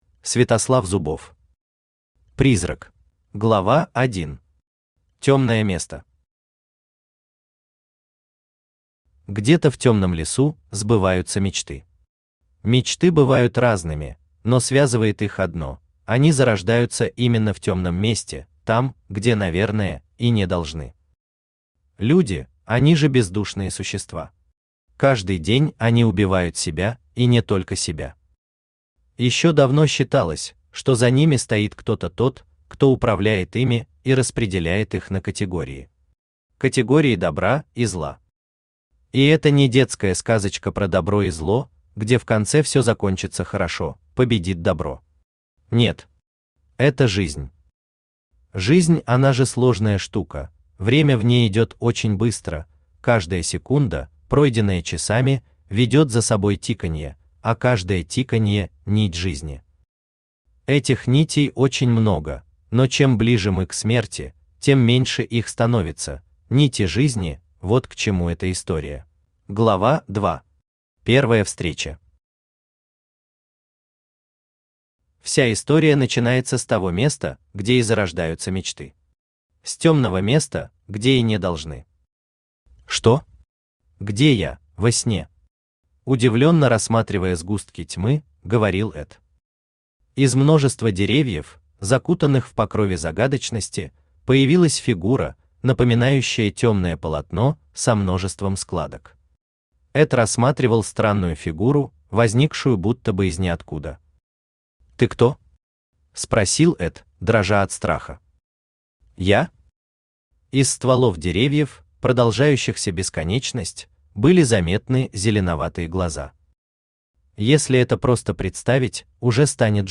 Аудиокнига Призрак | Библиотека аудиокниг
Aудиокнига Призрак Автор Святослав Андреевич Зубов Читает аудиокнигу Авточтец ЛитРес.